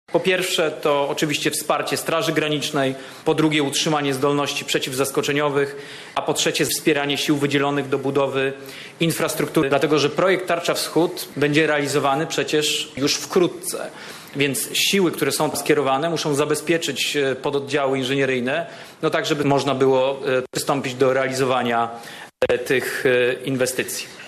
Podczas konferencji prasowej wiceszef MON podkreślił, że zadaniem żołnierzy uczestniczących w operacji Bezpieczne Podlasie będzie wsparcie Straży Granicznej.